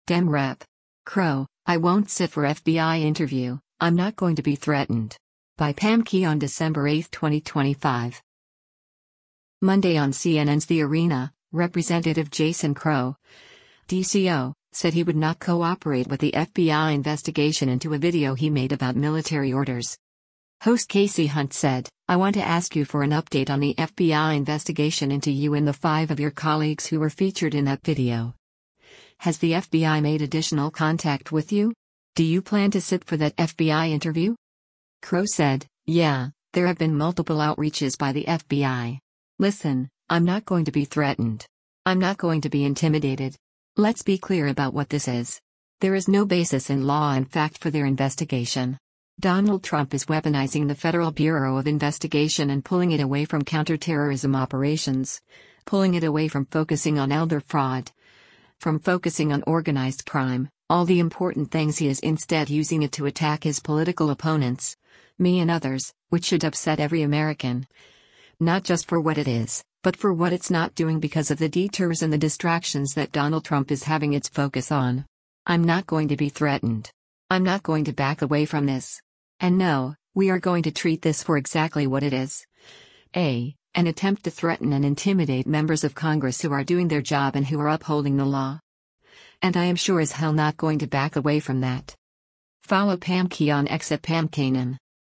Monday on CNN’s “The Arena,” Rep. Jason Crow (D-CO) said he would not cooperate with the FBI investigation into a video he made about military orders.